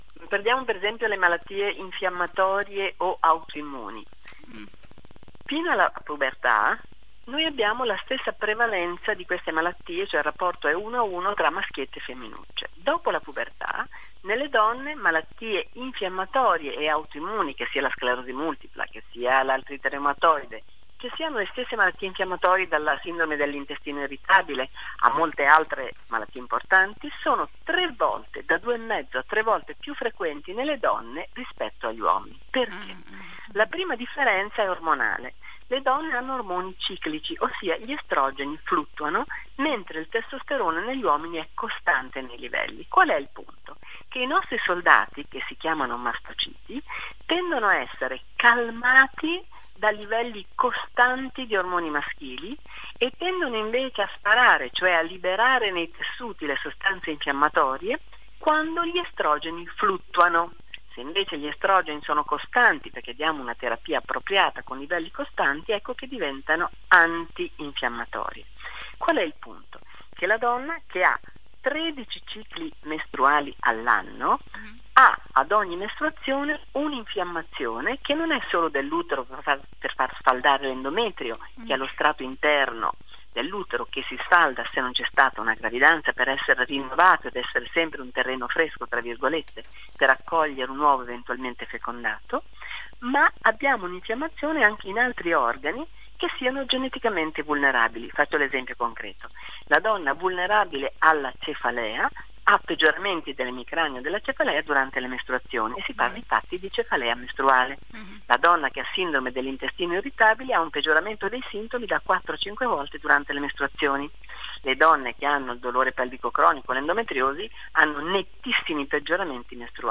Per gentile concessione di Radio Gamma Cinque